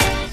Dre Synth Keyz.wav